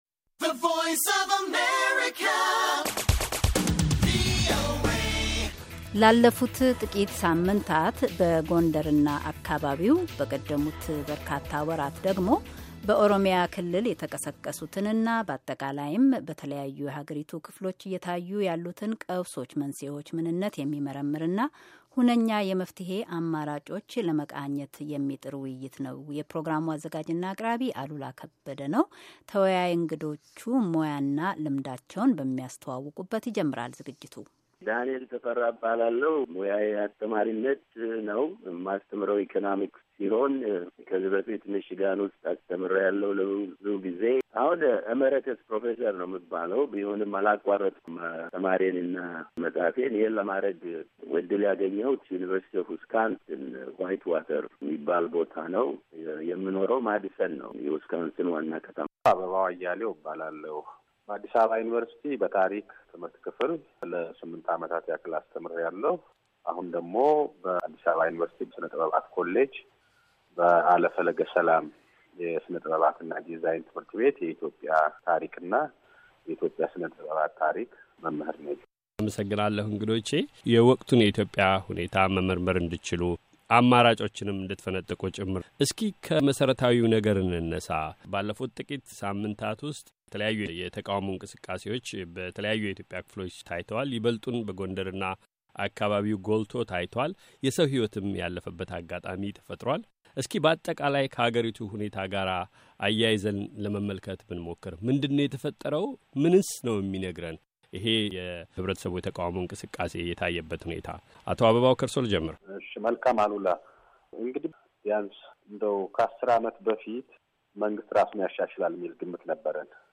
ውይይት:- ኢትዮጵያ የፖለቲካ ቀውሱ መንስኤዎች ምንነት አንድምታና ዘላቂ መፍትሄዎች